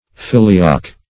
Filioque \Fil`i*o"que\, n. (Eccl. Hist.)